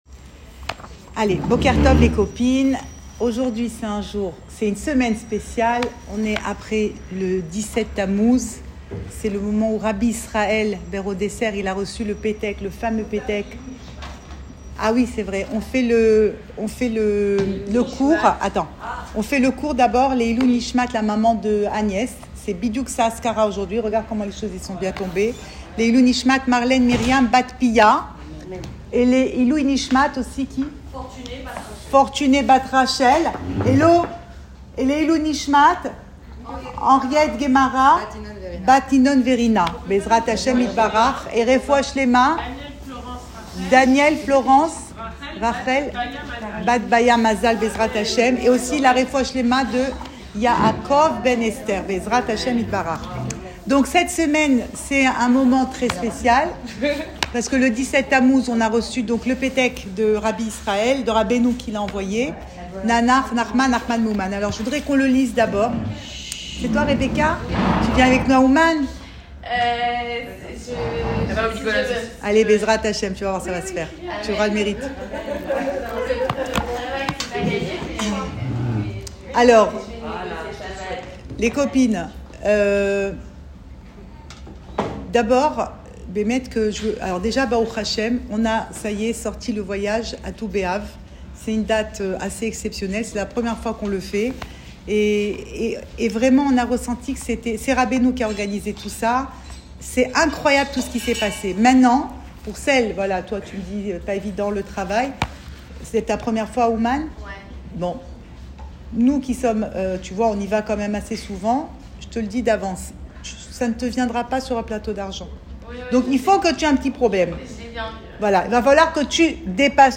Cours audio
Enregistré à Tel Aviv